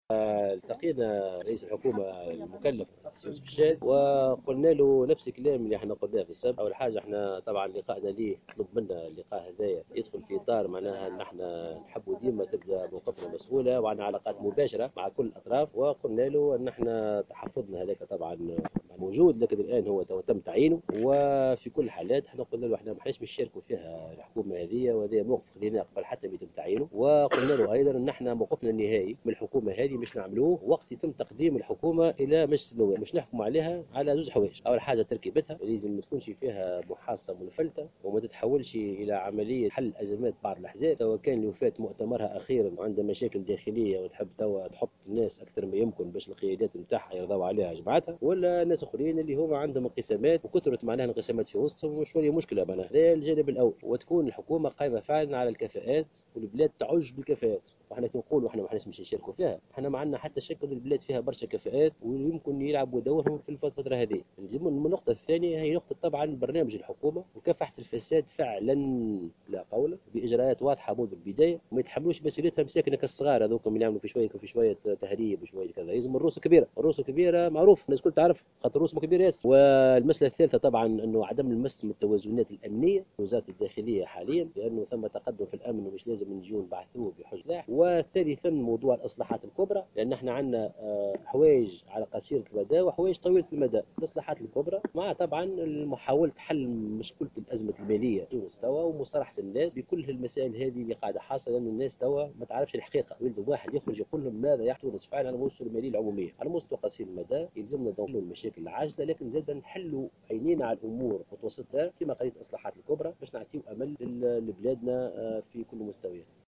أكد أمين عام حركة مشروع تونس، محسن مرزوق في تصريح اعلامي اليوم الإثنين 8 أوت 2016 عقب لقائه اليوم برئيس الحكومة يوسف الشاهد أن حزبه جدد تحفظه على المشاركة في الحكومة القادمة.